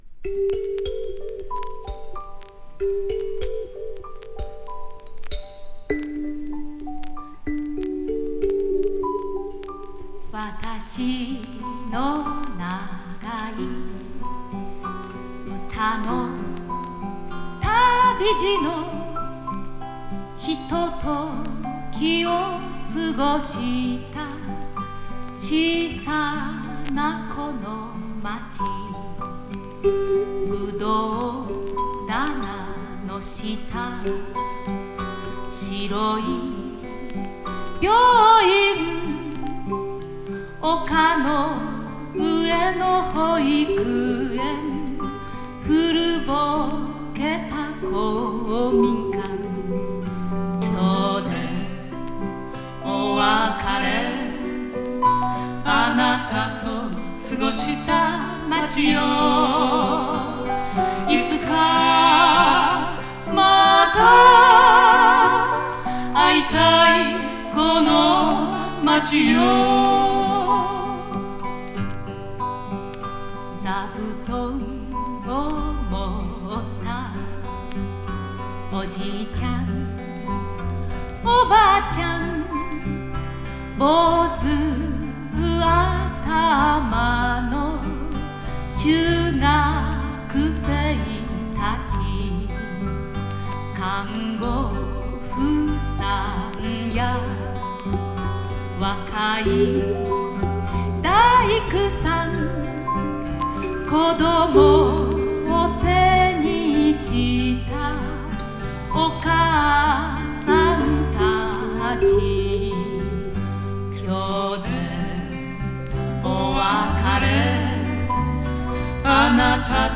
女性SSW